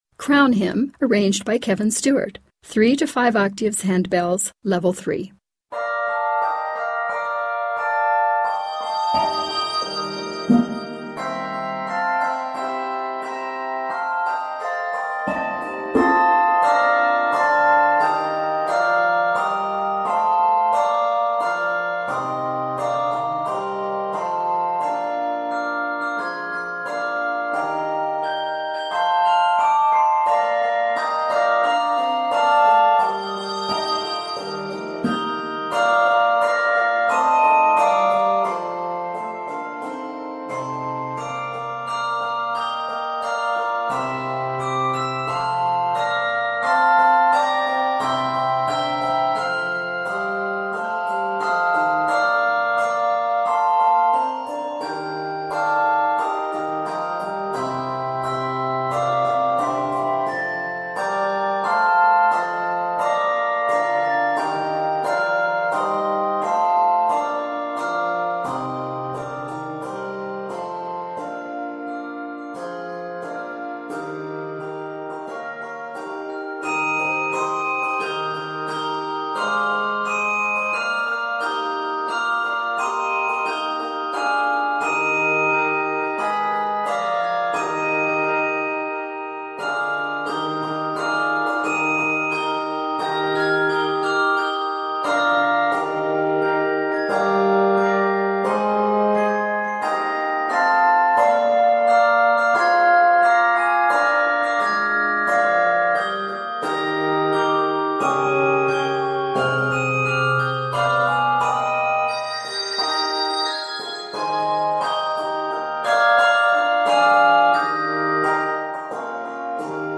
A stately arrangement combining three familiar hymn tunes